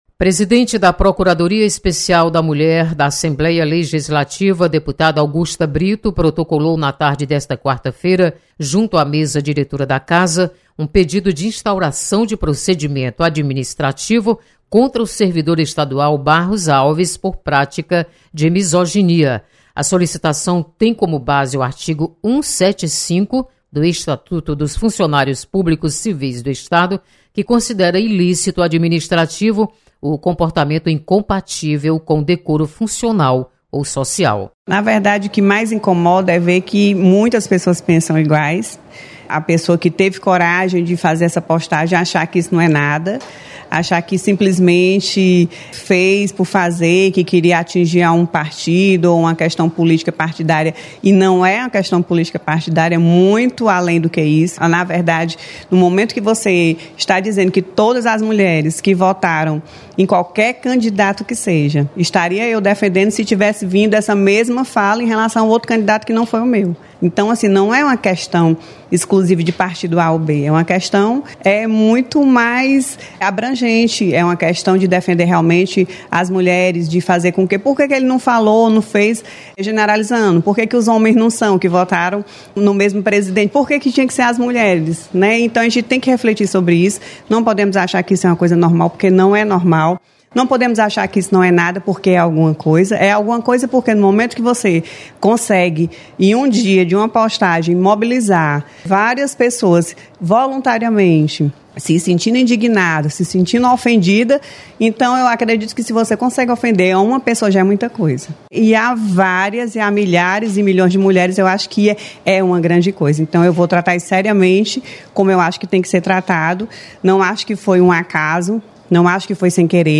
• Fonte: Agência de Notícias da Assembleia Legislativa